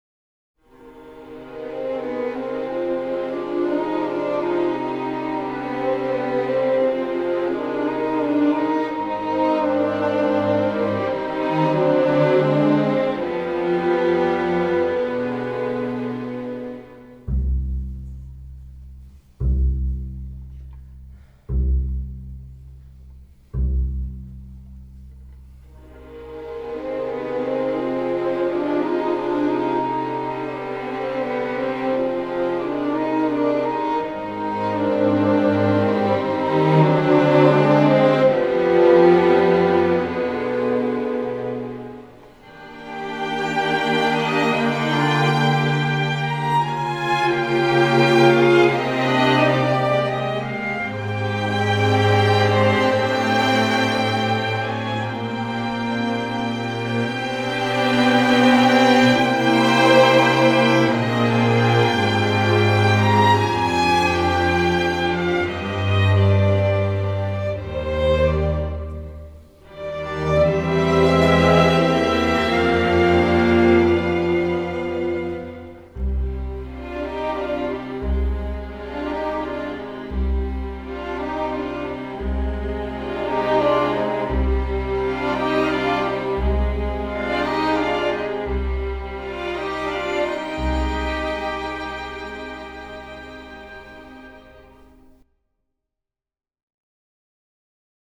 Strings Slow Version